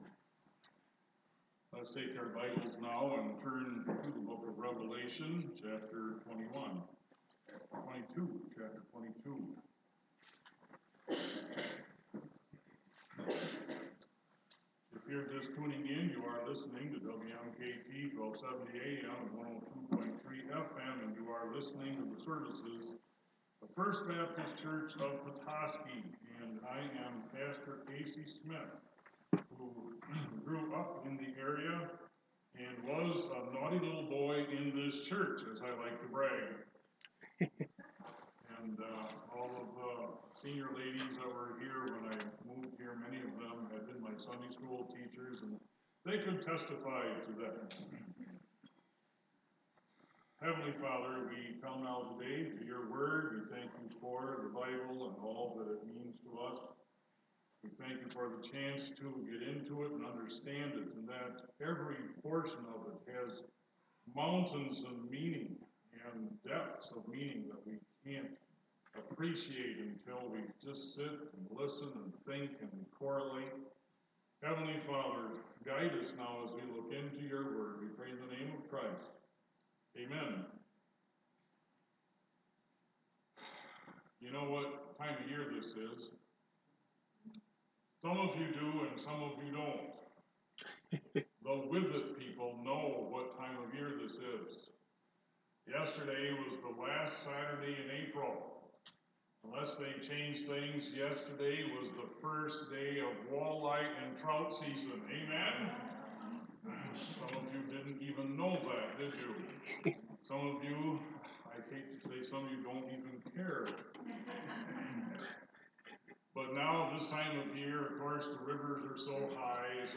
Sunday Morning Messages